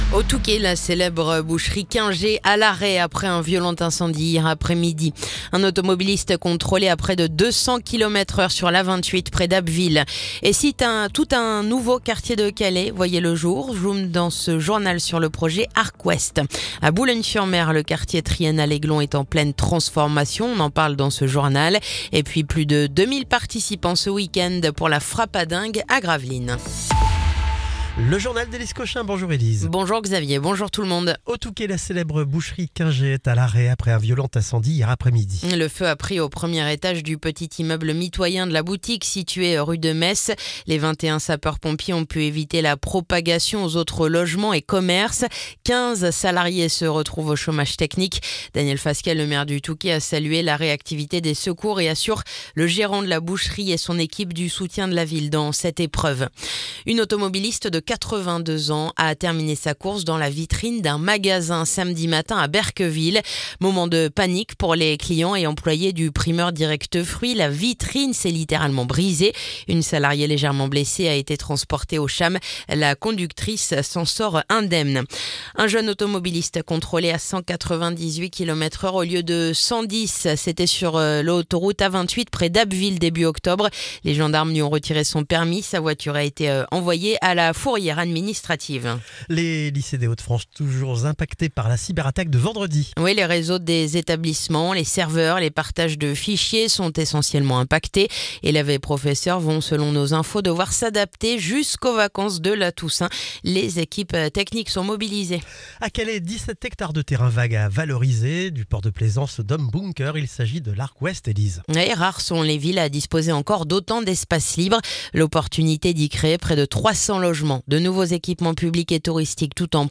Le journal du lundi 13 octobre